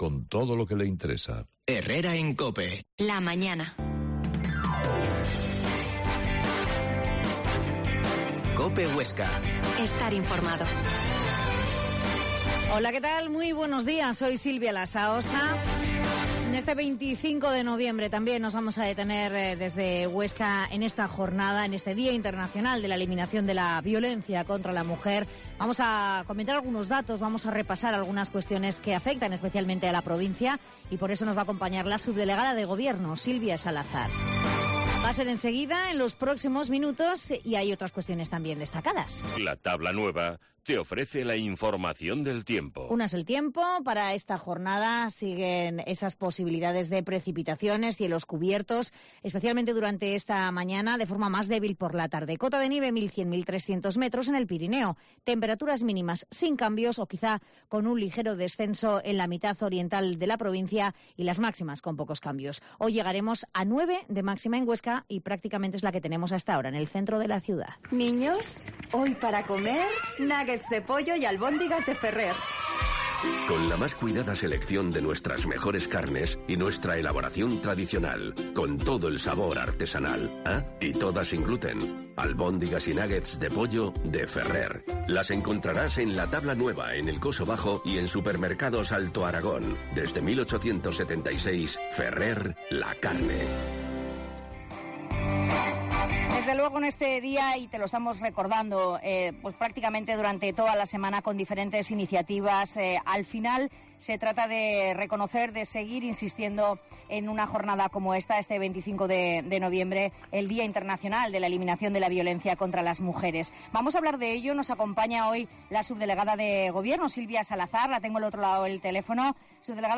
Herrera en Cope Huesca 12,50h. Entrevista a la subdelegada de Gobierno en Huesca, Silvia Salazar